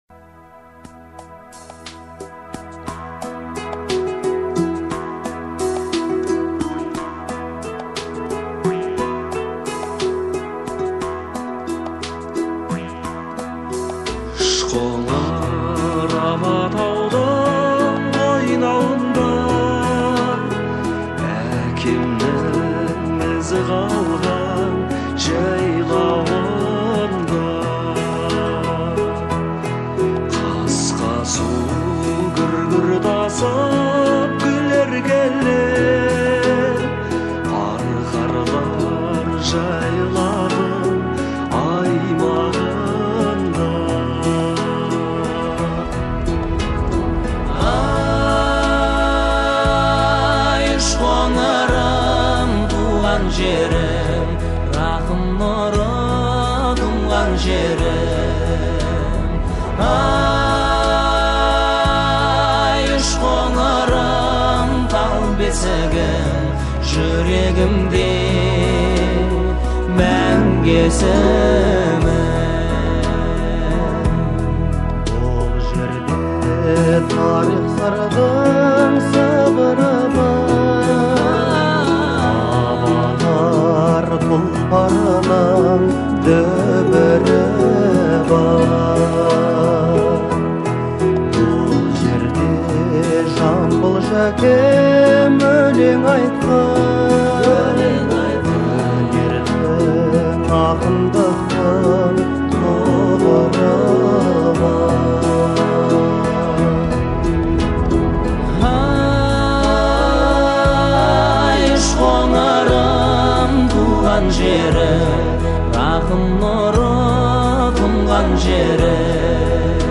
трогательная и мелодичная песня
выполненная в жанре поп-музыки.